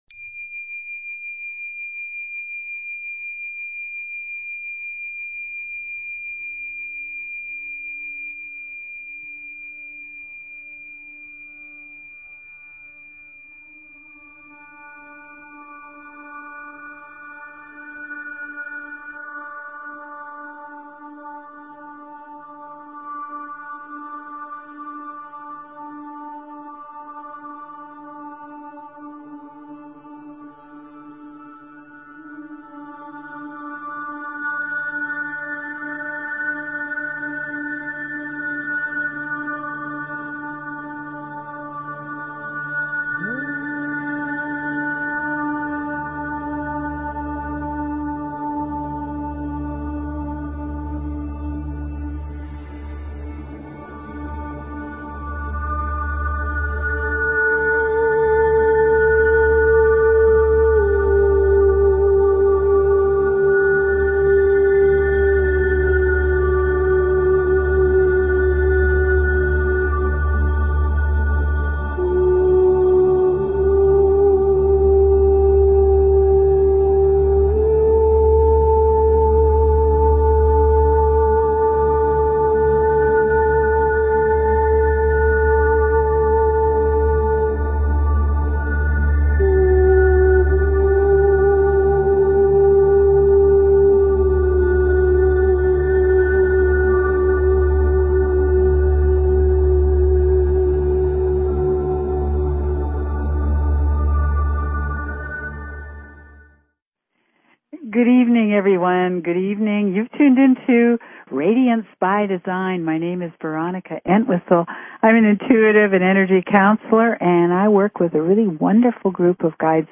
Talk Show Episode, Audio Podcast, Radiance_by_Design and Courtesy of BBS Radio on , show guests , about , categorized as
Radiance By Design is specifically tailored to the energies of each week and your calls dictate our on air discussions.